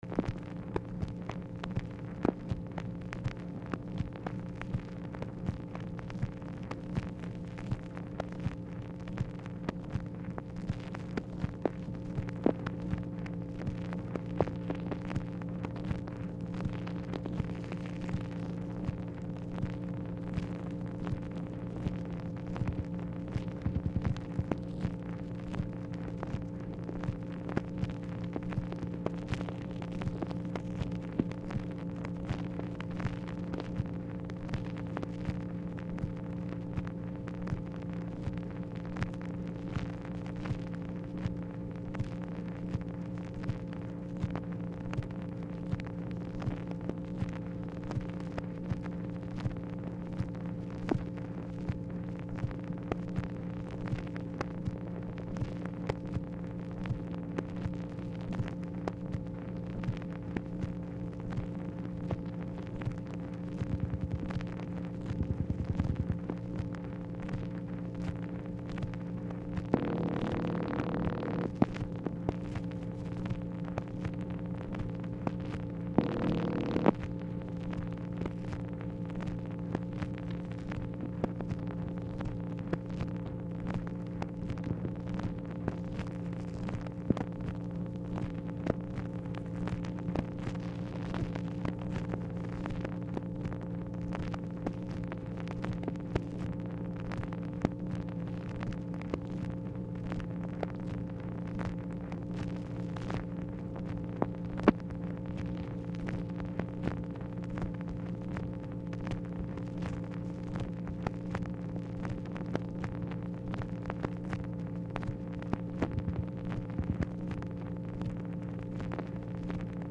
Telephone conversation # 4862, sound recording, MACHINE NOISE, 8/10/1964, time unknown | Discover LBJ
Format Dictation belt
Location Of Speaker 1 Oval Office or unknown location